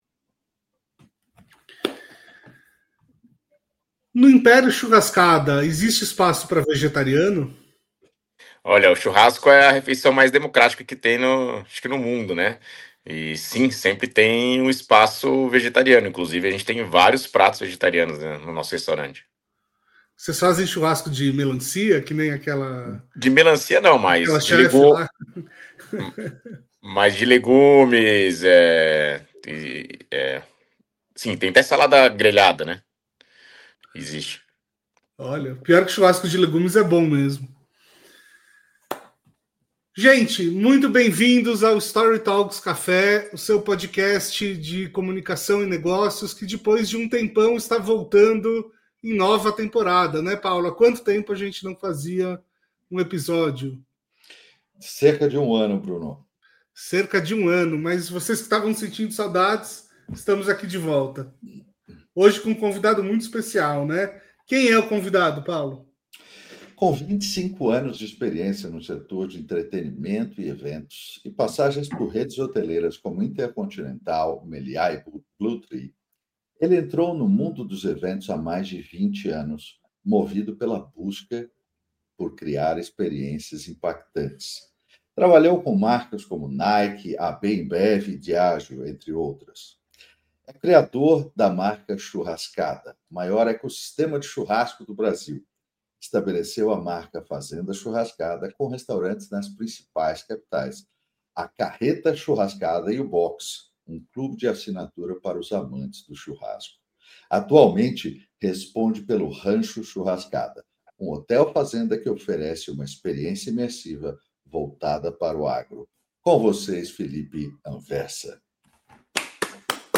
Programa de entrevistas da consultoria StoryTalks.
Conteúdo originalmente transmitido ao vivo via YouTube.